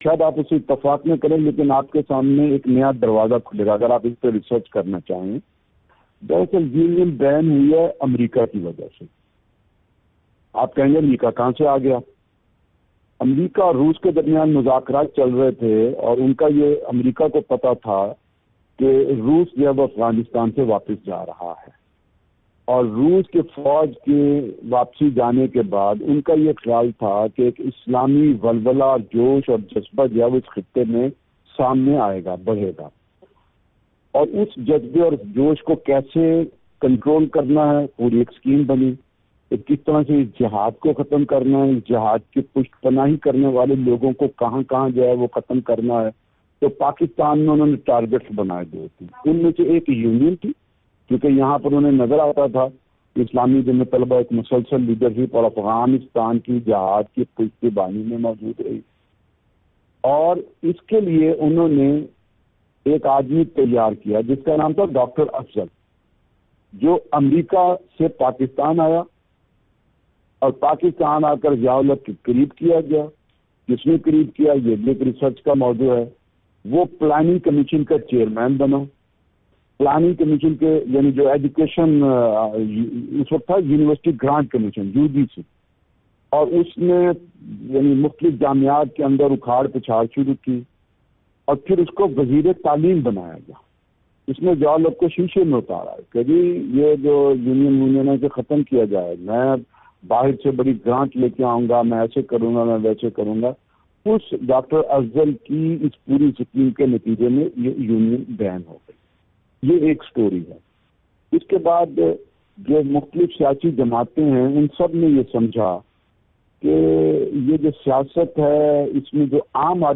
اس سلسلے میں جماعت اسلامی کے سیکرٹری جنرل اور سابق طالب علم رہنما امیر العظیم سے خصوصی گفتگو پیش کی جا رہی ہے۔